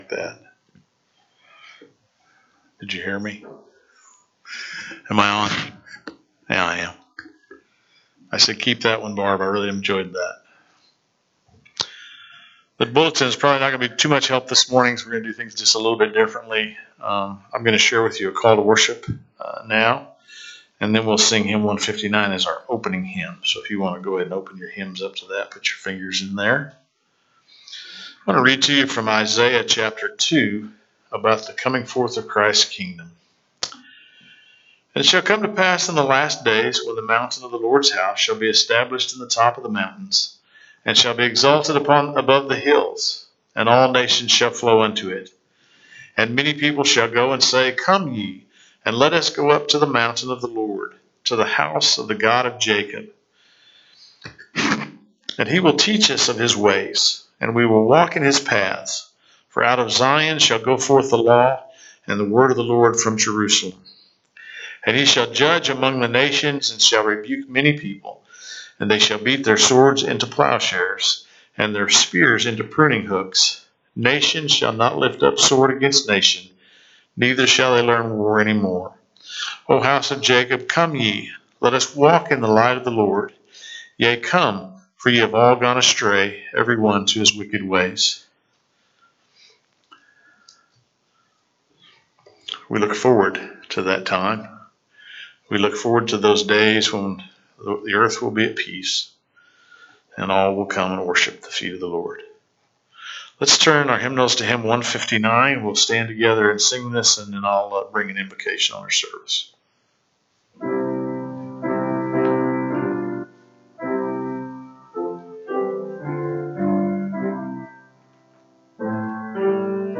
Lexington Restoration Branch - March 16, 2025 Service - Playeur